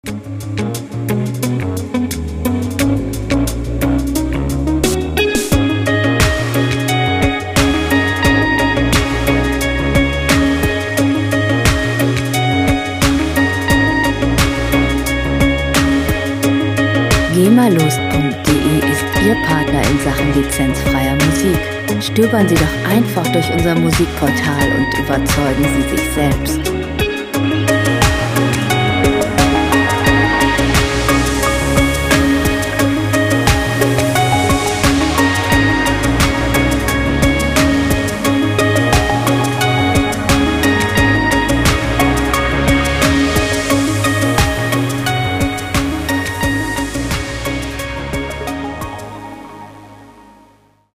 Werbemusik - Dynamik und Bewegung
Musikstil: Electro Lounge
Tempo: 88 bpm
Tonart: Gis-Moll
Charakter: ruhig, ausgeglichen
Instrumentierung: Indie Guitars, Synthesizer